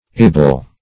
ible - definition of ible - synonyms, pronunciation, spelling from Free Dictionary
-ible \-i*ble\